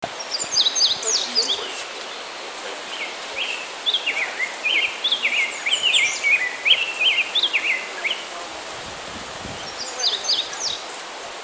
See if you can identify the birds singing, all recorded during the trip: